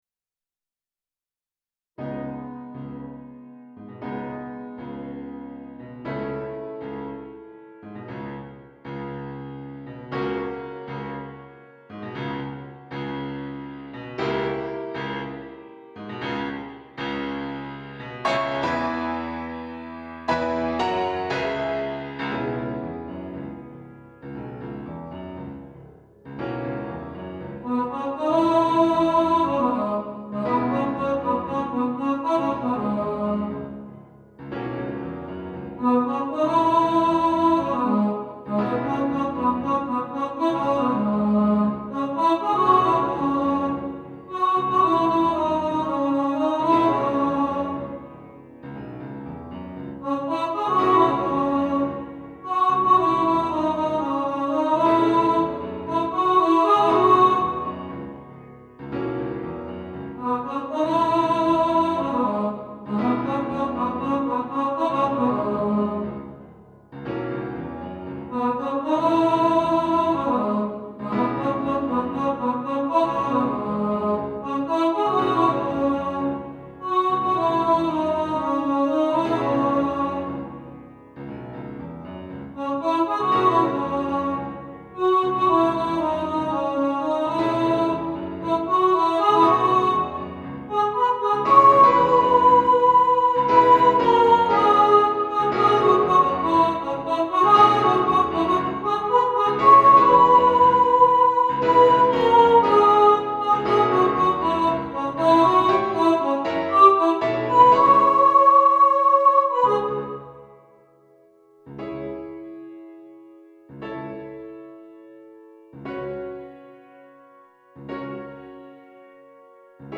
Thriller Soprano | Ipswich Hospital Community Choir
"Thriller Soprano".
Thriller-Soprano.mp3